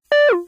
phaserDown1.ogg